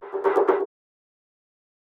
Z Shutter.wav